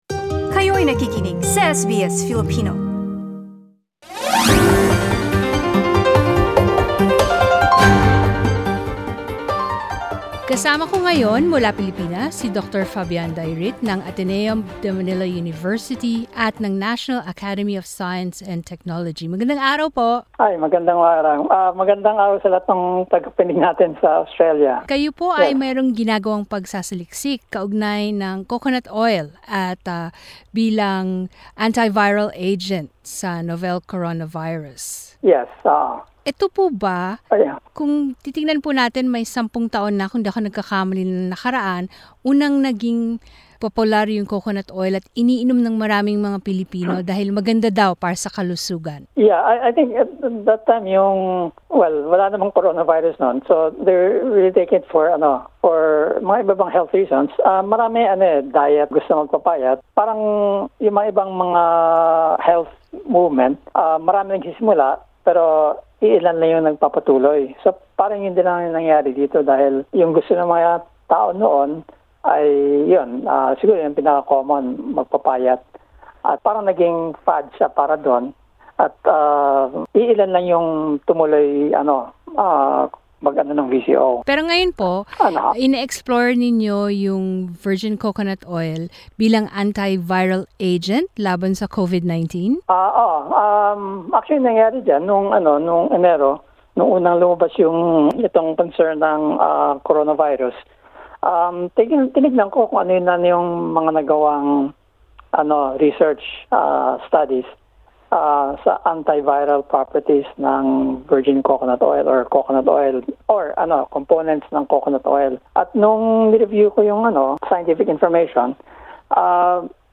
ang inyong sarili' Pakinggan ang aming panayam